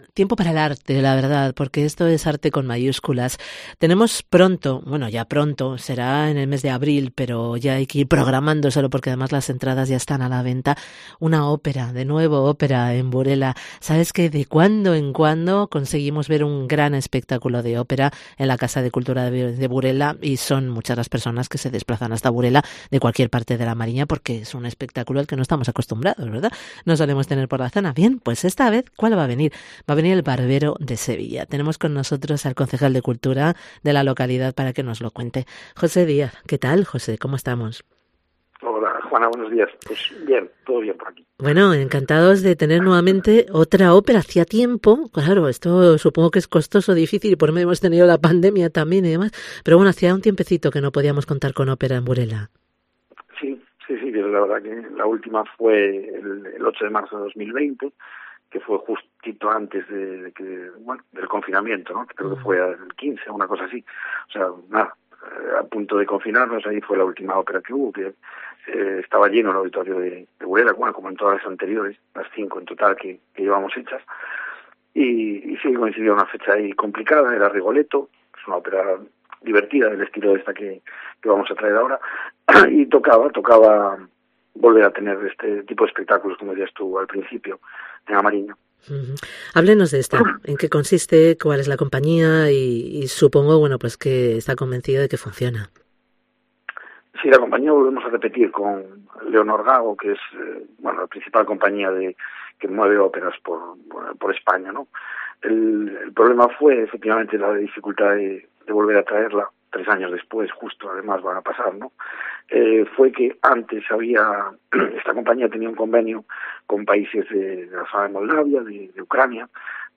Además de hablarnos de la próxima ópera -"El Barbero de Sevilla" - en esta entrevista el concejal de Cultura, José Díaz , nos cuenta sus planes de futuro. Pasan por no formar parte de ninguna lista electoral para los próximos comicios de mayo. Está cansado porque la Cultura es un mundo muy absorbente y deja poco espacio para otras cosas.